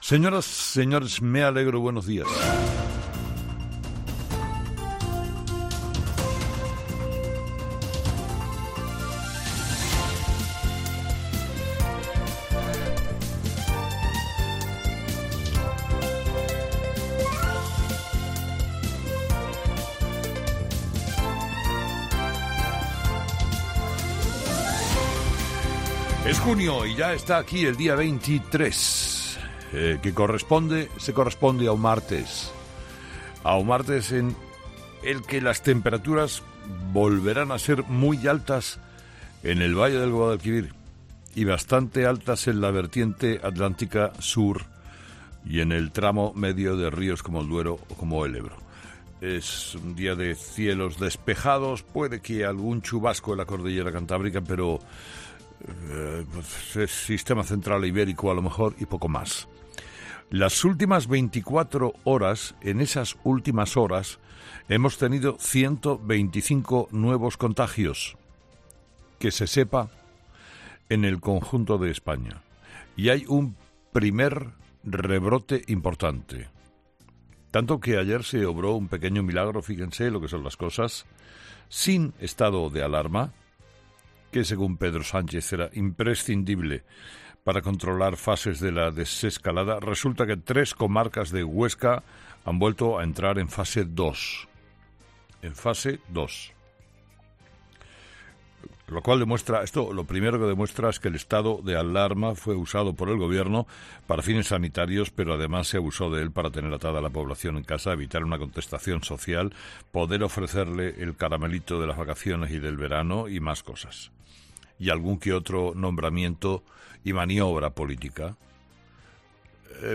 En su monólogo de las seis de la mañana, Carlos Herrera ha comentado la última hora de los distintos rebrotes de coronavirus activos en estos momentos en España.